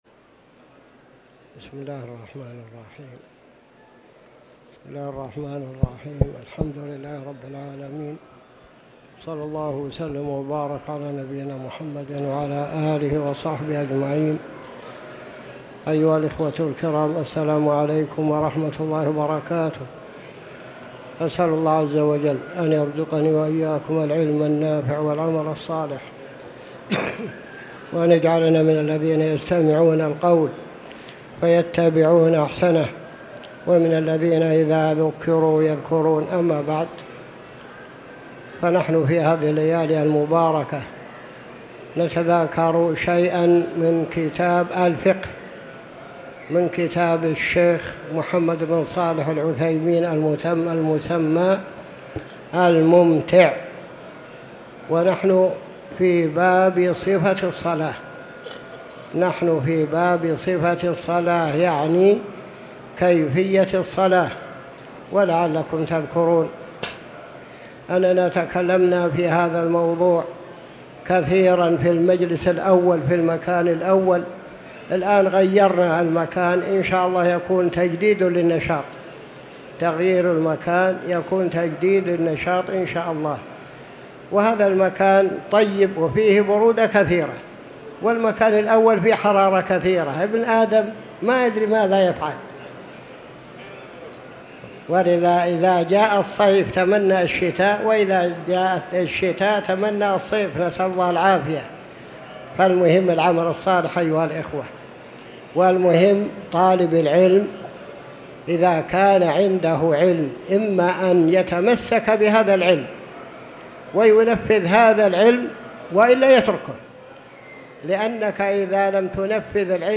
تاريخ النشر ٢٦ شوال ١٤٤٠ هـ المكان: المسجد الحرام الشيخ